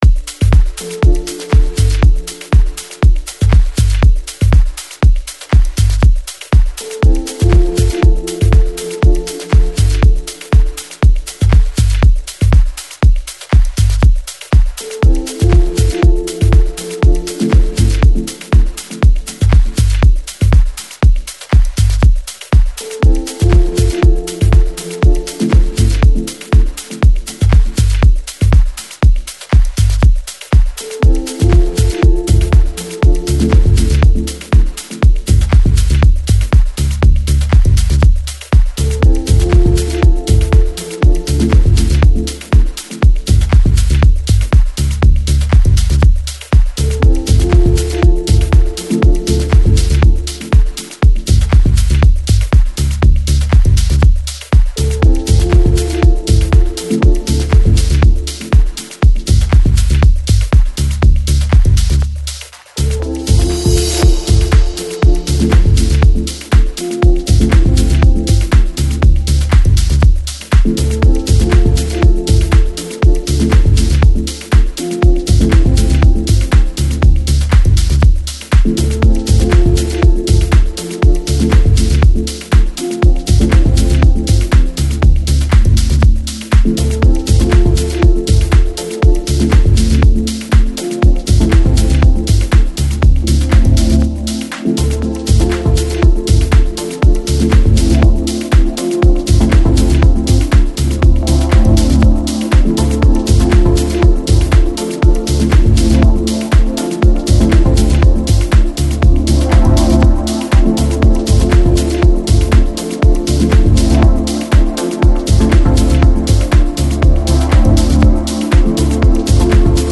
音乐流派: Deep House